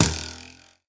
bowhit3.ogg